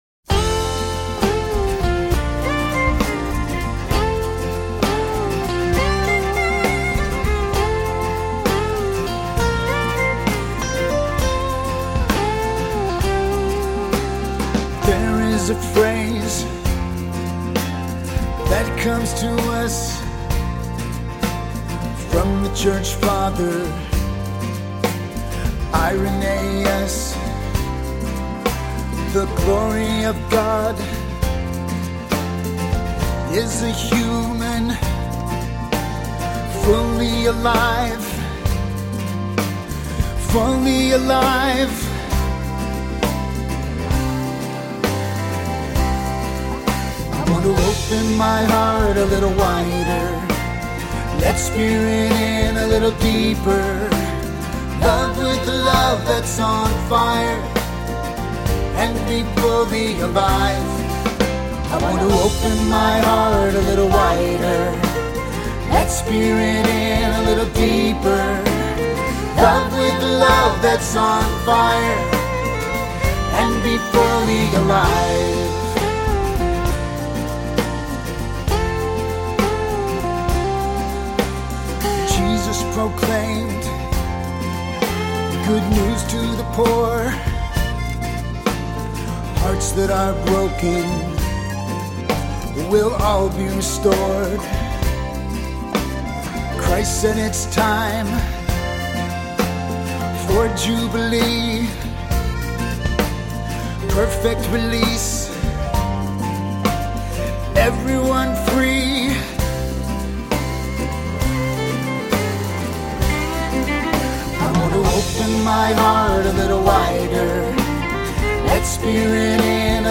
This upbeat song